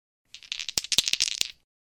dice.mp3